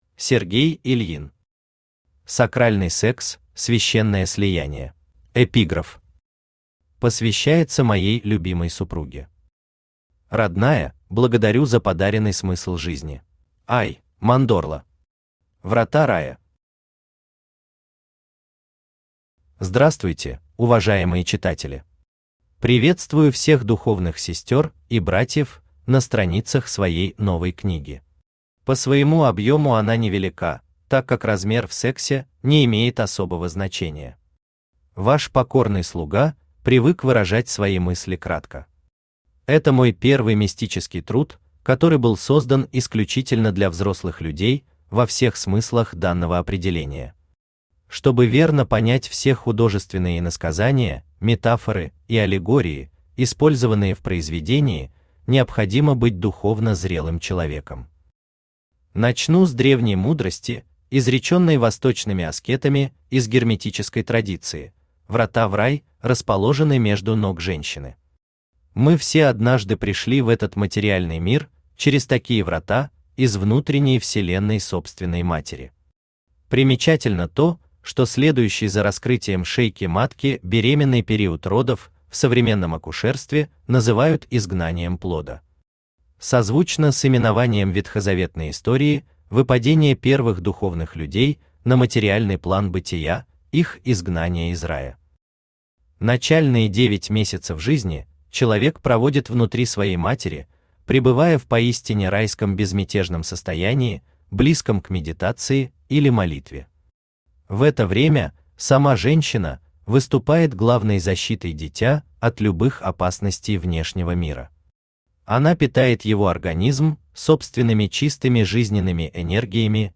Аудиокнига Сакральный секс: священное слияние | Библиотека аудиокниг
Читает аудиокнигу Авточтец ЛитРес.